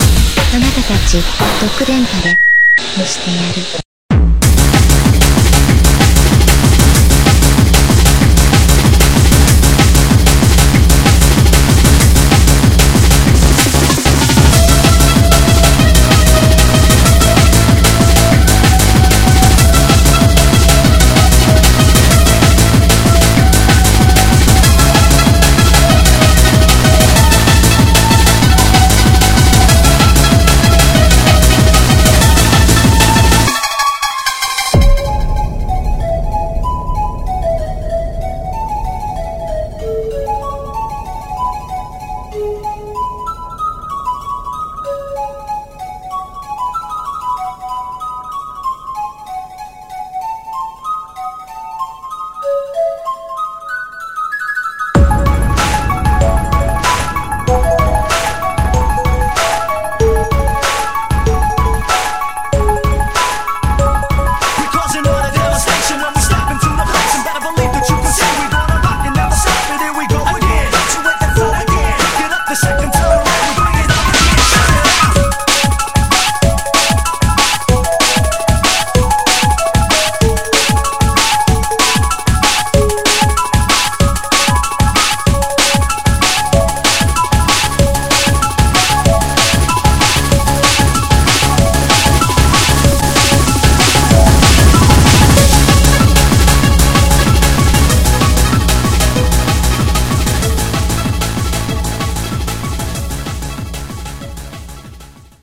BPM49-190
Speed.